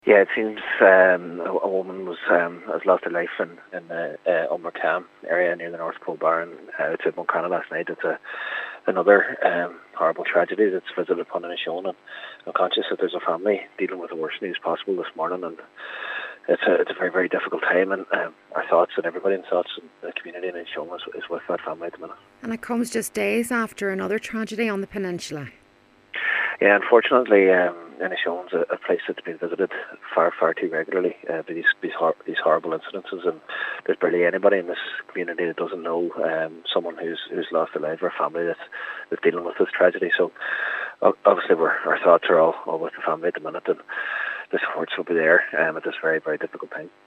Cathaoirleach of the Inishowen Municipal District Cllr Jack Murray: